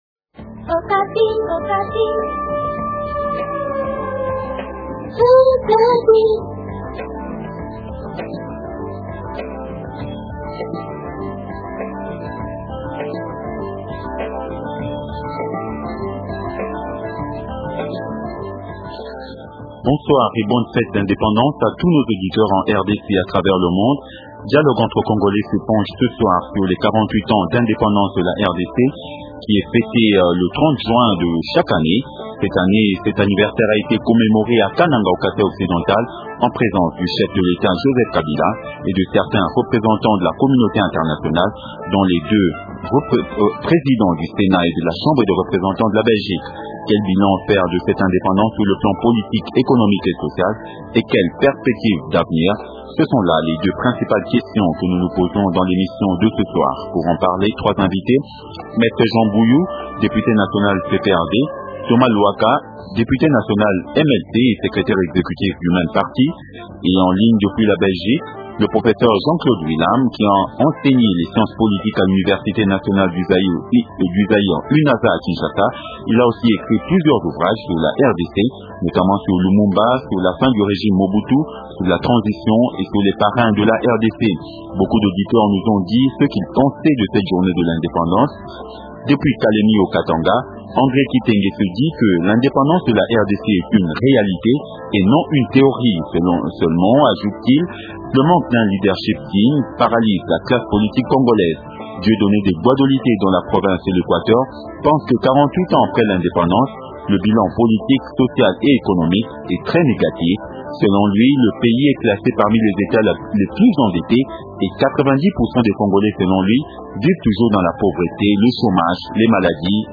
Maître Mbuyu, député national Pprd